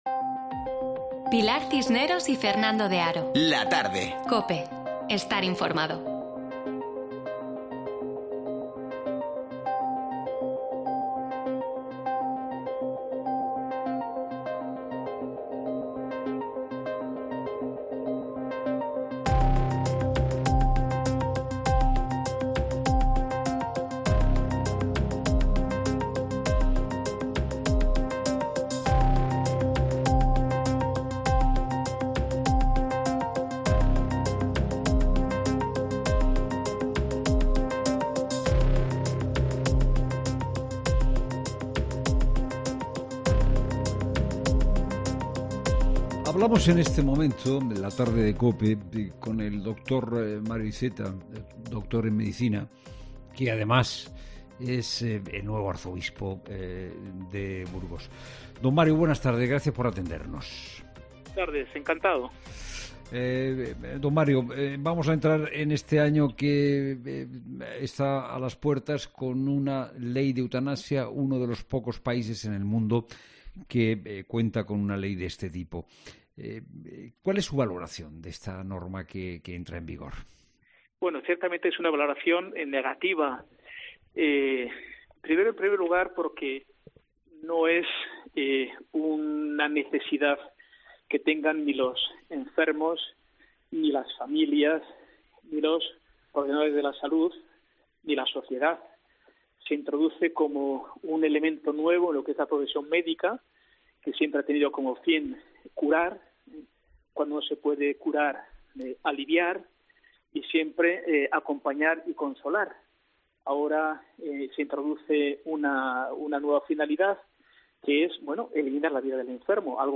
En una entrevista concedida a 'La Tarde', Mons. Mario Iceta niega que la normativa cuente con el respaldo social e insta a las administraciones a...